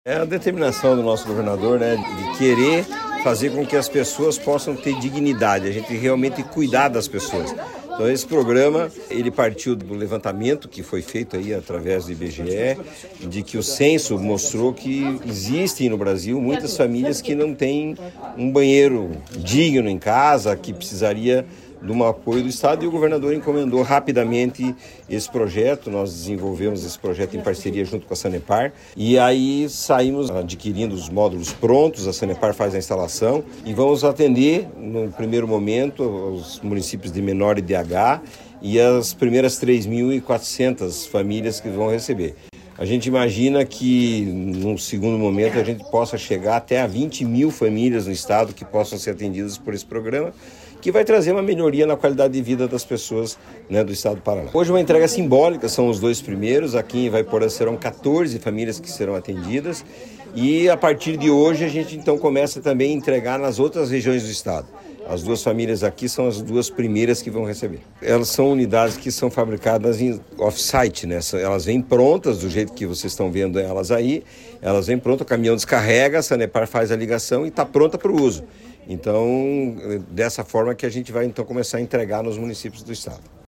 Sonora do presidente da Cohapar, Jorge Lange, sobre a entrega dos primeiros módulos sanitários do programa Banheiro em Casa em Ivaiporã